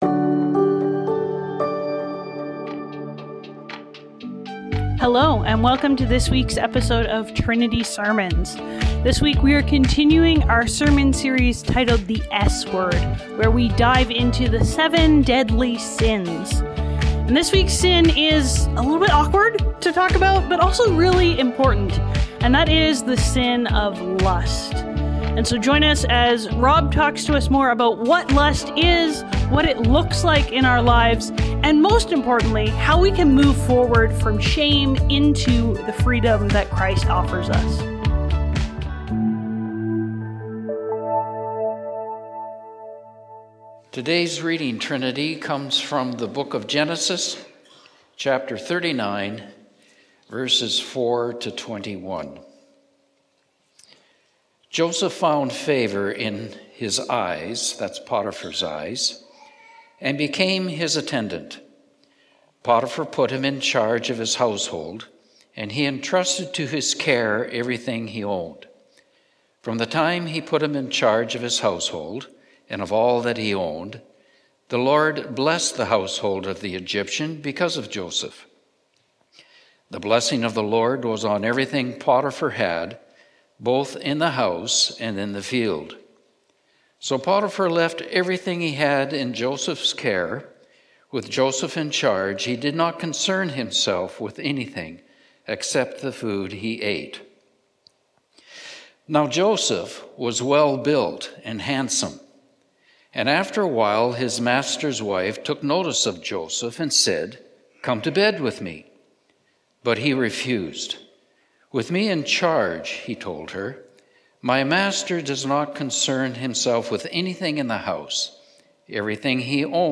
Trinity Streetsville - The Lure of Lust | The "S" Word | Trinity Sermons - Archive FM